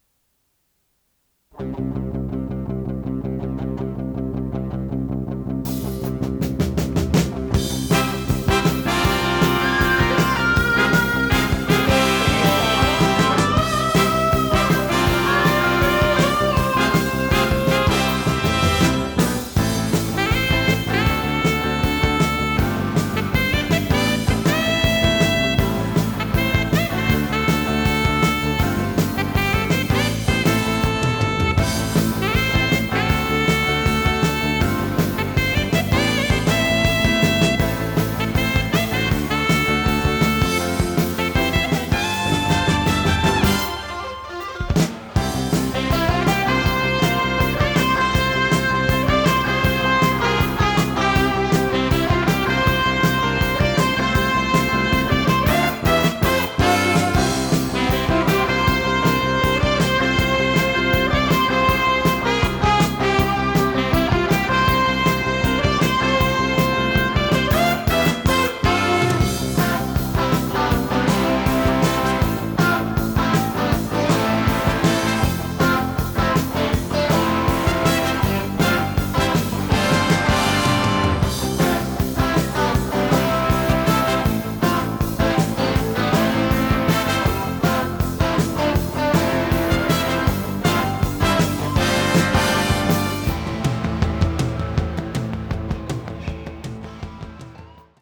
テープ：Nakamichi ZX （純正メタルテープ）
ノイズリダクションOFF
【フュージョン・ロック】容量53.8MB